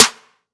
Asap Clap2.wav